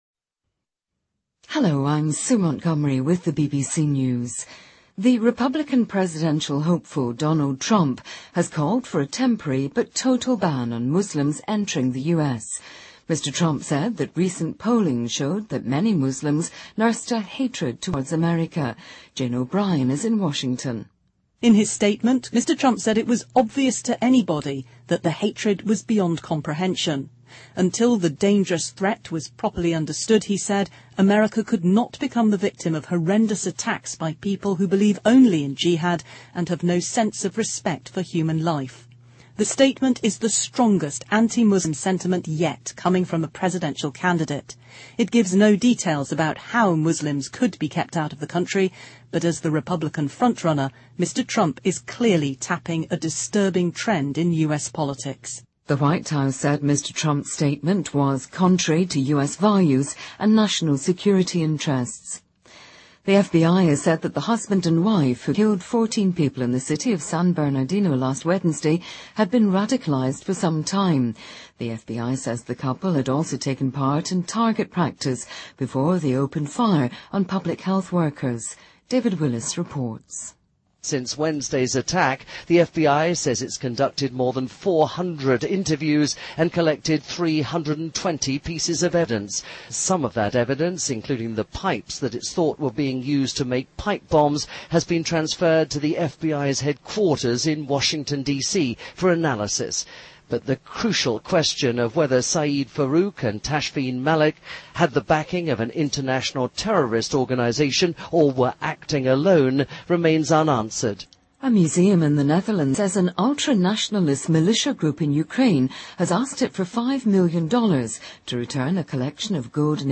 BBC news,特朗普发表反穆斯林言论